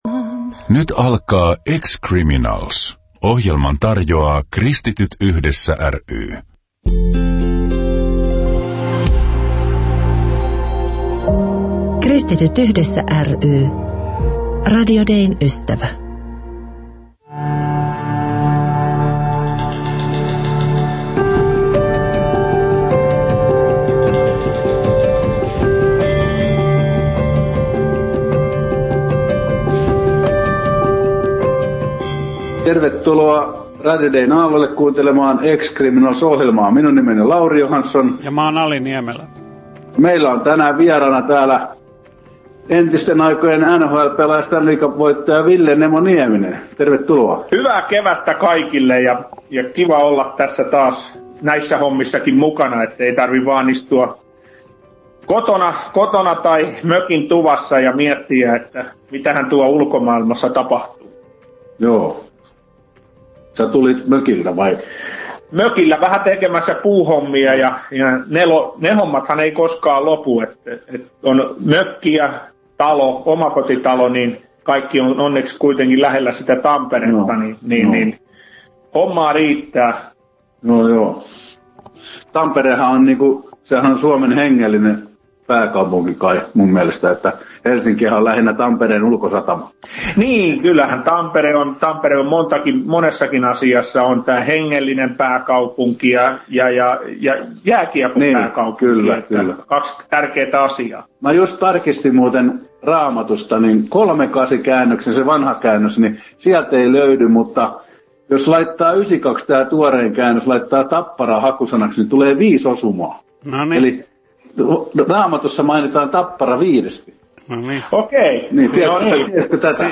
Kuuntele Ville Niemisen haastattelu 2.6.2020: